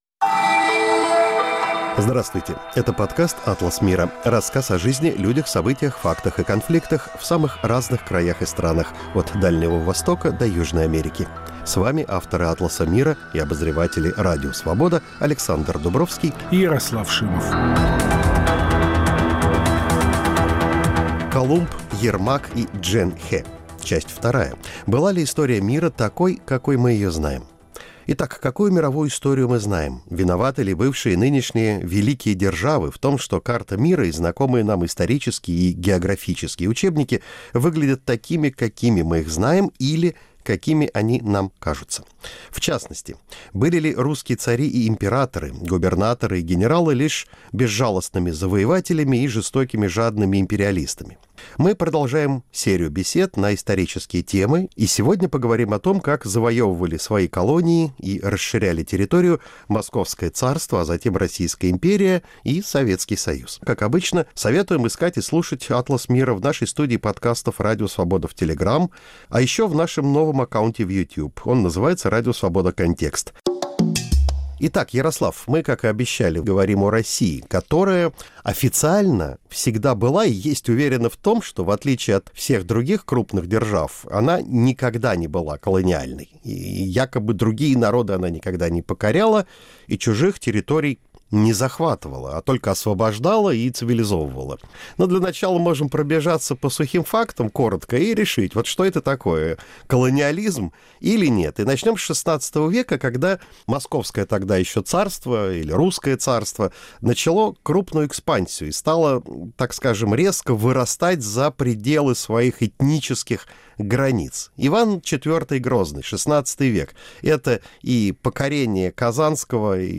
Мы продолжаем цикл бесед о переоценках истории. В новом выпуске – беседа о том, как завоевывали свои колонии и расширяли территорию Московское царство, а затем Российская империя и СССР.